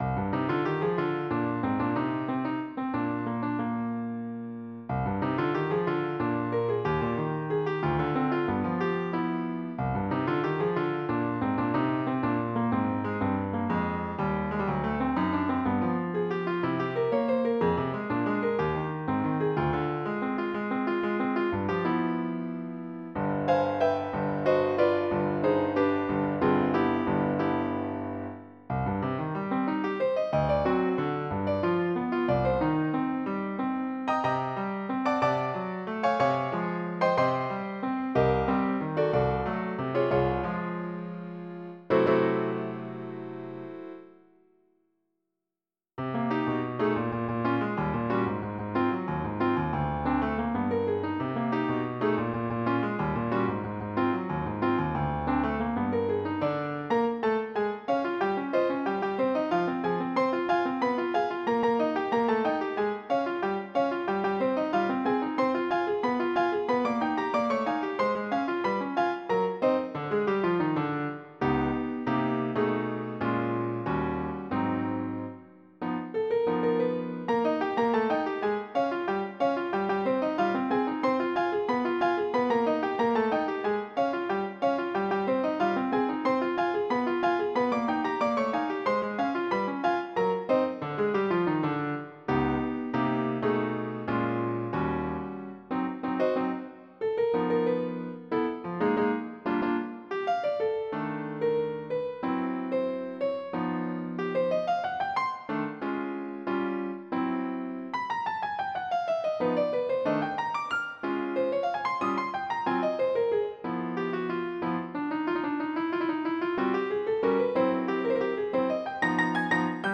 PIANO NOTA A NOTA PDF + MIDI